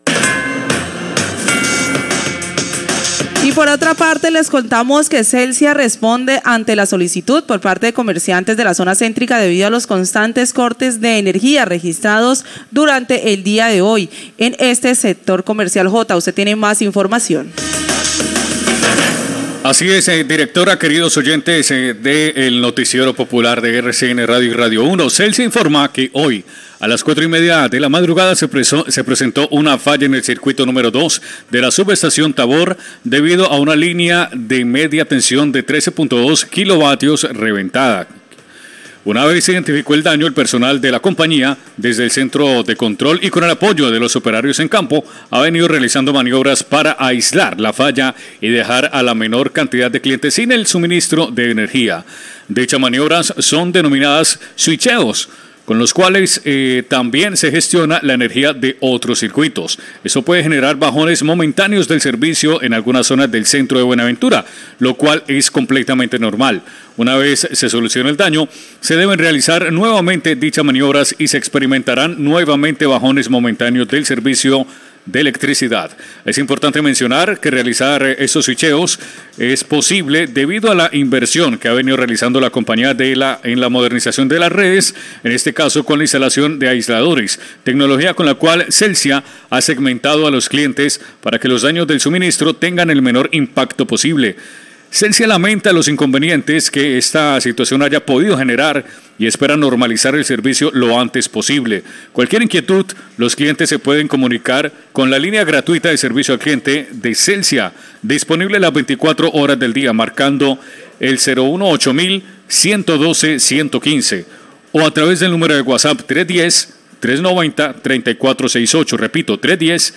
NOTICIERO POPULAR
comunicado de prensa